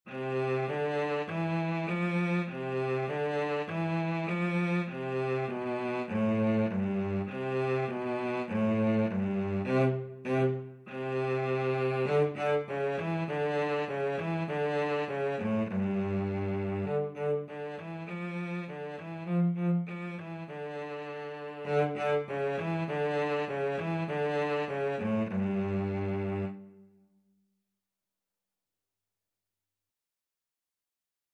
4/4 (View more 4/4 Music)
G3-F4
Cello  (View more Beginners Cello Music)
Classical (View more Classical Cello Music)